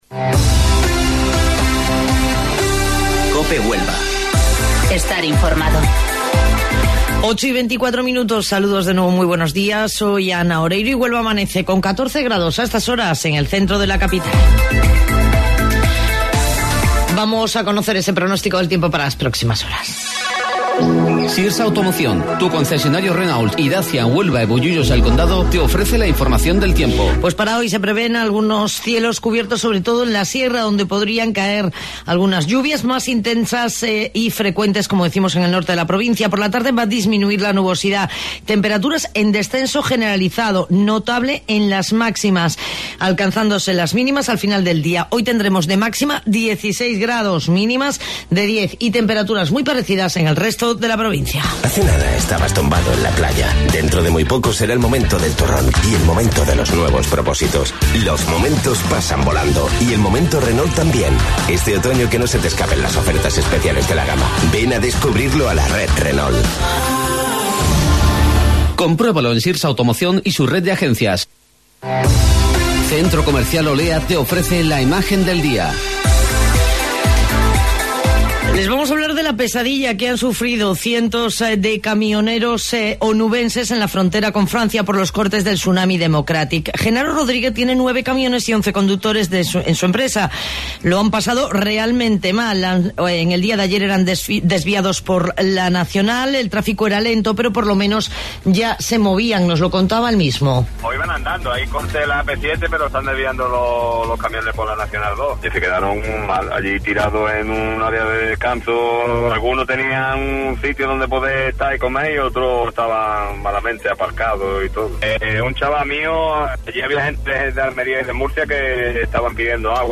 AUDIO: Informativo Local 08:25 del 14 de Noviembre